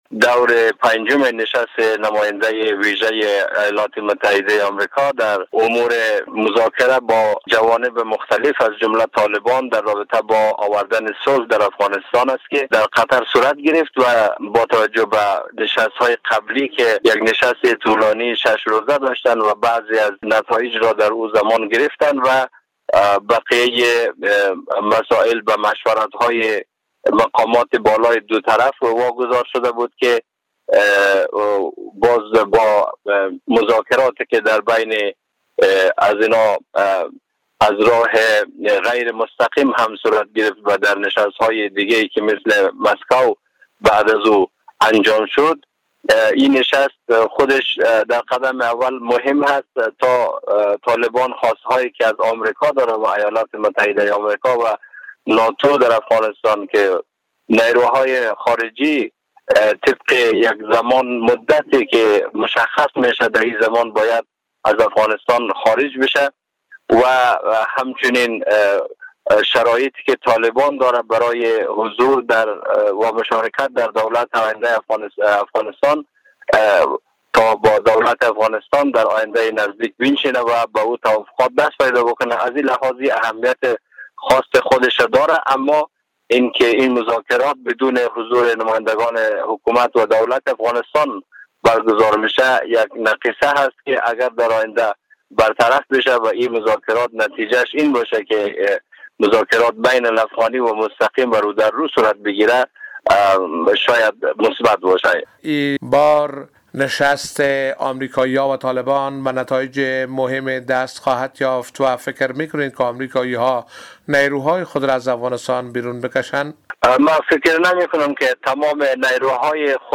گفت و گو با خبرنگار رادیو دری